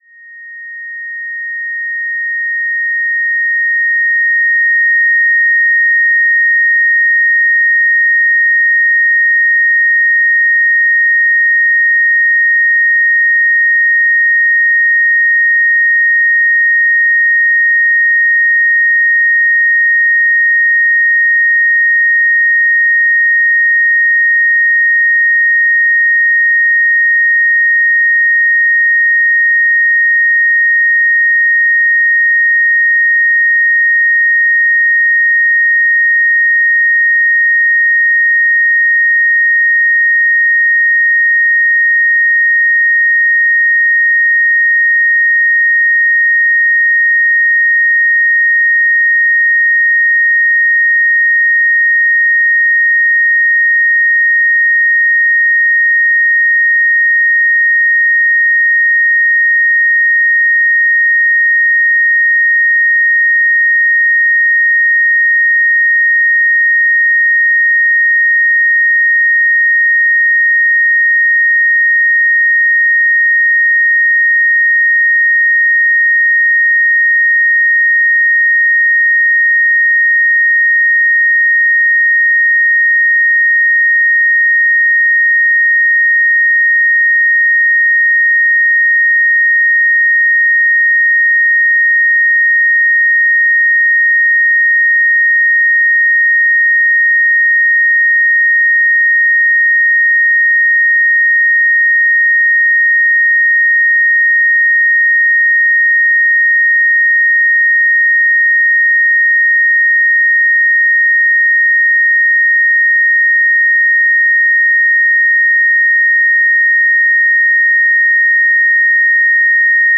It consists essentially of a cycle of pitches, initially highly consonant in super-particular ratios (n:n+1) to a given drone, but gradually increasing in dissonance.
The B-flat drone rises at the end by a whole-tone.
untone8-Drone-1.mp3